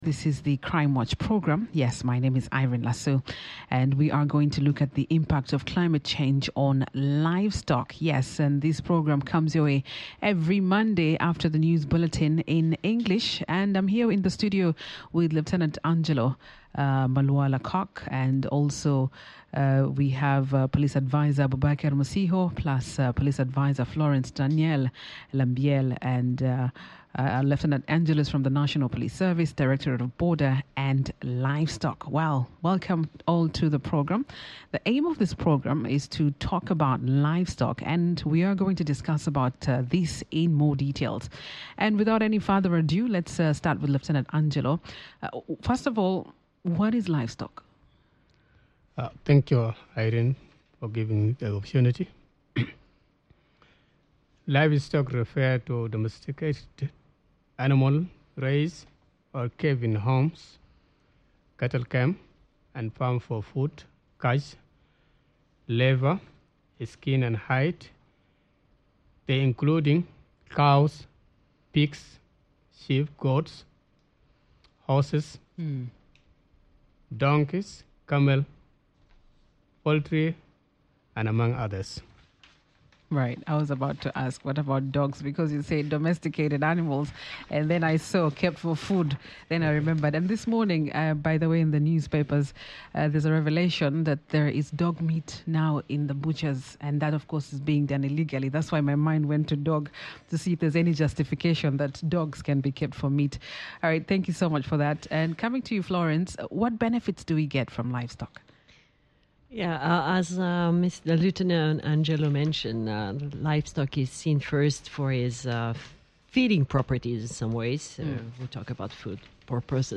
Police experts discuss key laws, including the Wildlife Conservation and National Parks Act, the Livestock Policy, Animal Health Regulations, and Livestock Marketing Regulations. They look at how these rules aim to safeguard animal health, regulate trade, and protect the environment through measures like Environmental Impact Assessments. The programme also examine the growing challenges posed by climate change on livestock quality and quantity.